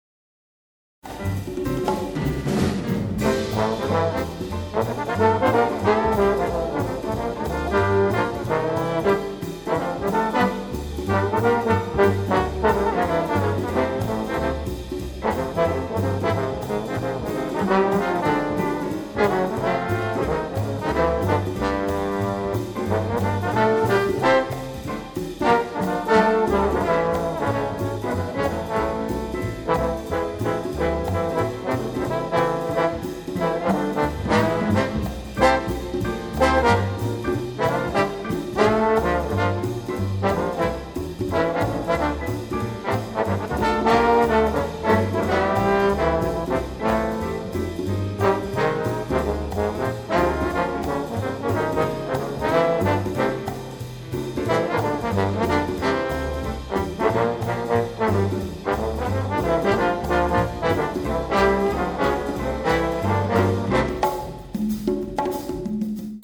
Jazz.
• Category: Trombone Quintets w/Rhythm Section